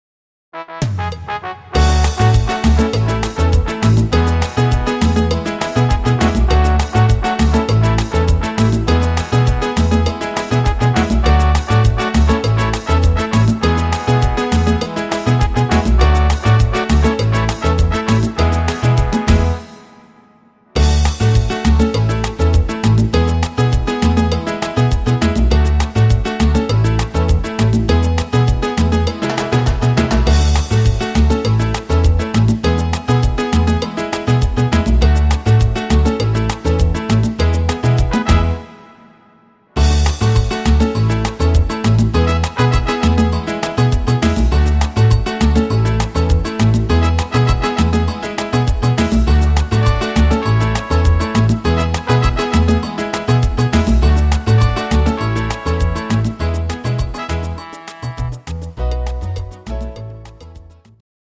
echter Salsa Rhythmus